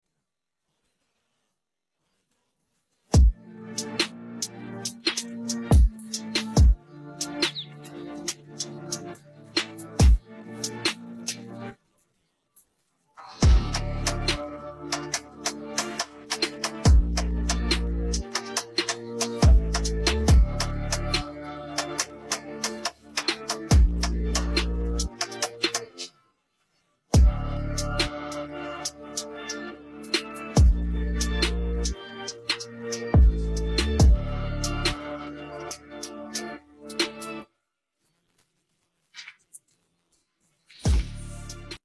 Pozadí hudba